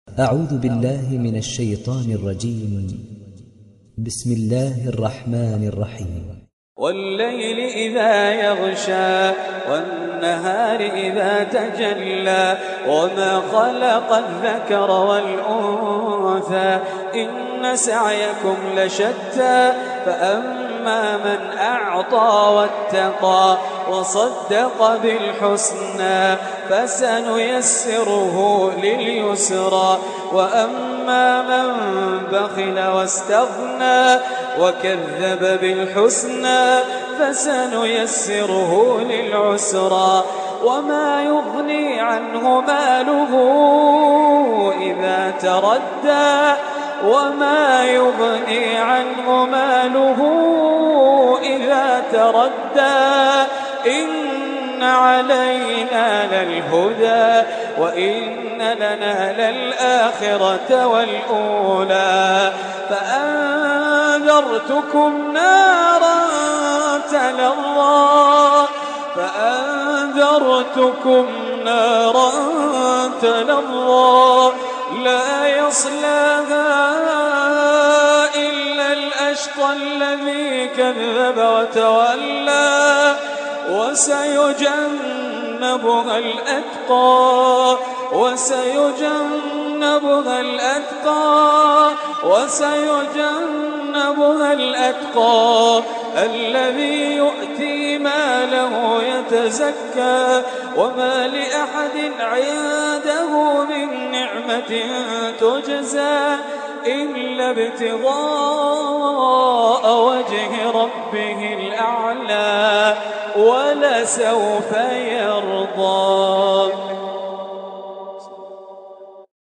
دانلود سوره الليل mp3 خالد الجليل روایت حفص از عاصم, قرآن را دانلود کنید و گوش کن mp3 ، لینک مستقیم کامل